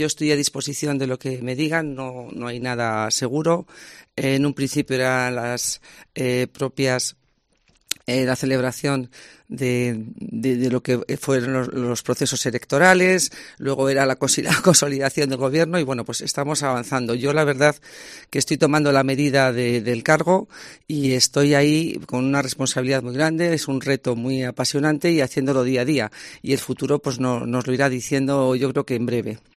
Marian Rueda, subdelegada del Gobierno en Segovia, sobre su futuro en el cargo